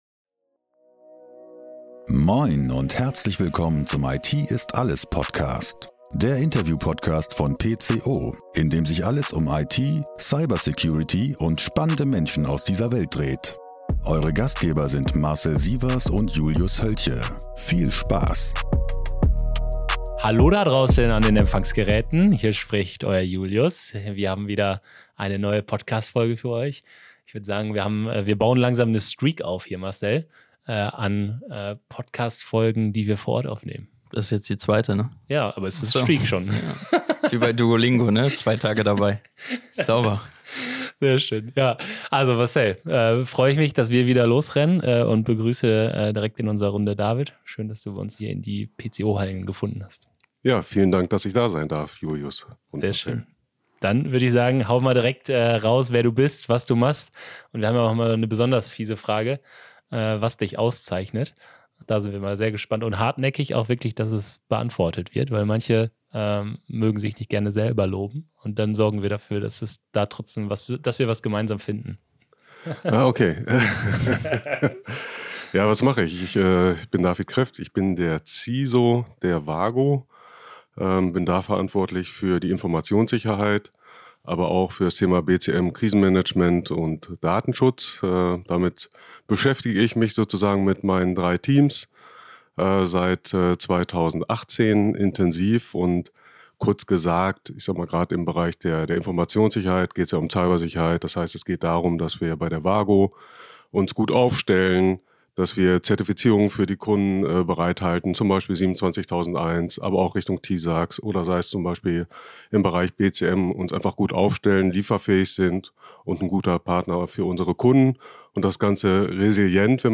Die Drei sprechen darüber, wie WAGO Informationssicherheit lebt und wie der Reifegrad von WAGO aussieht.